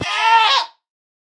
Minecraft Version Minecraft Version latest Latest Release | Latest Snapshot latest / assets / minecraft / sounds / mob / goat / screaming_hurt3.ogg Compare With Compare With Latest Release | Latest Snapshot
screaming_hurt3.ogg